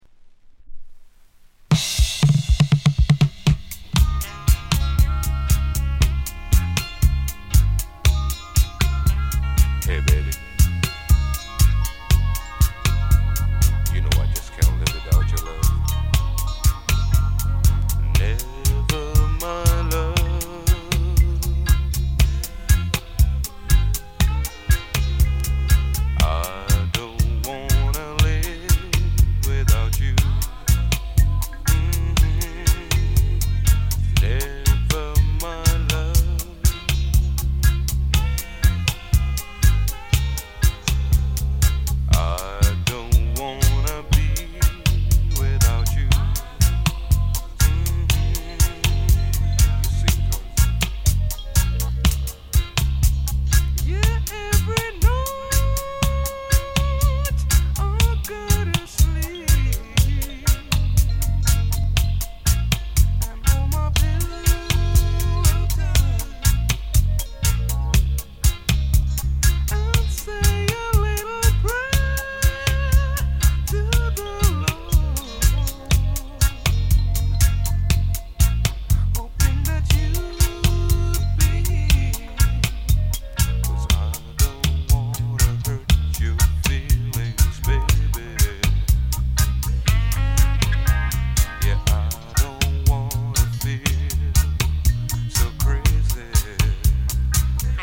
UK 大人の LOVERS, DUB 逸品